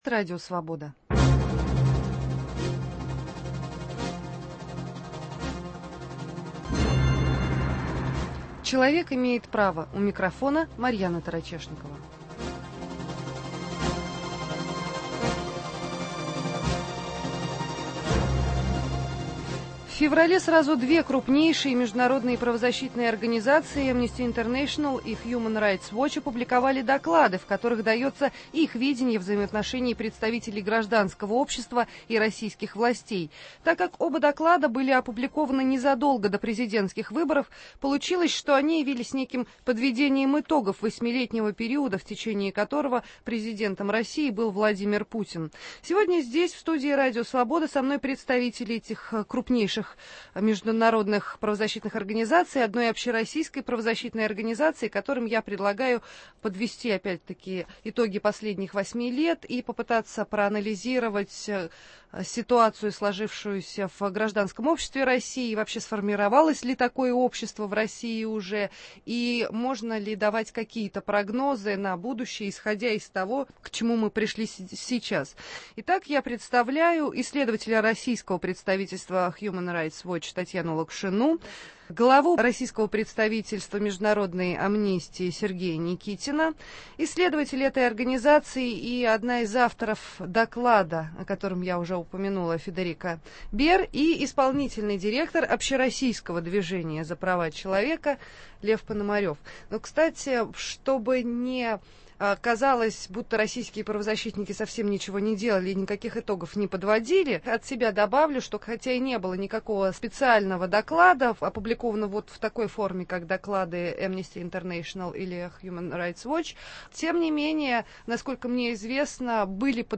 Как изменилось отношение российских властей к представителям общественных организаций в последние восемь лет? Почему международные правозащитные организации заявляют о противодействии государства проявлениям независимой гражданской активности? В студии РС